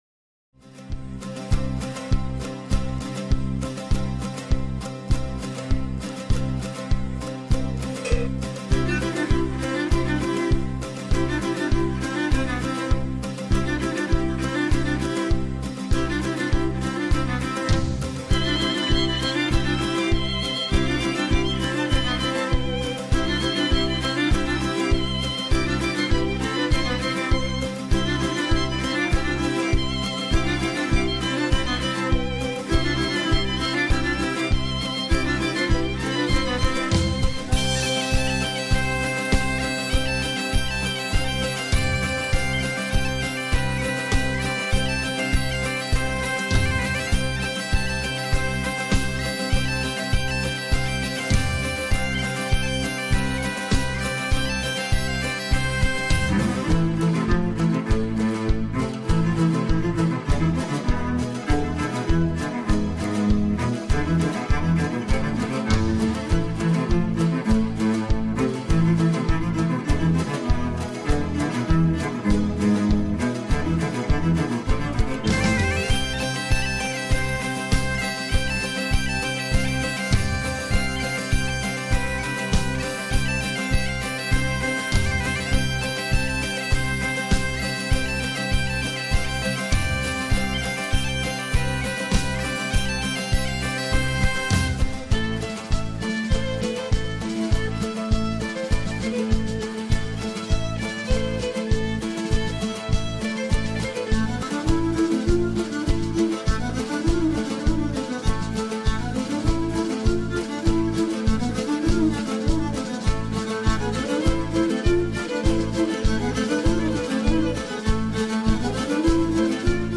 Brincadeira - Base Musical